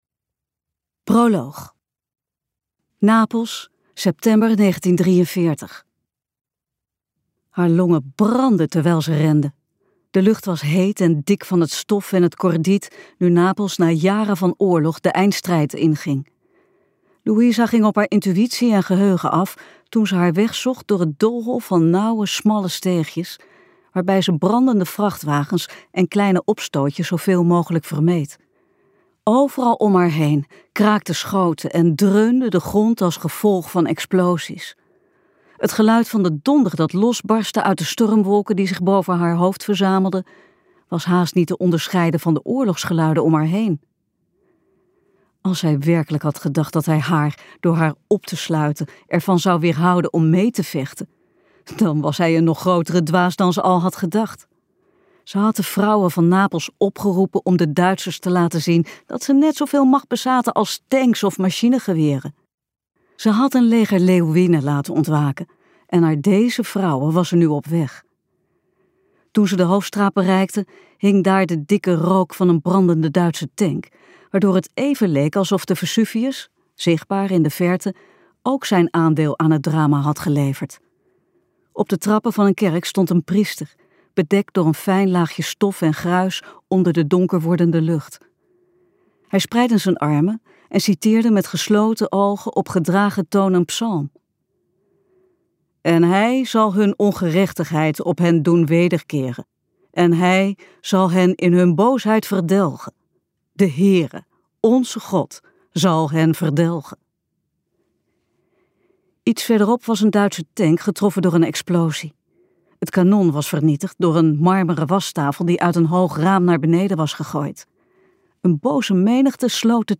KokBoekencentrum | De stem van napels luisterboek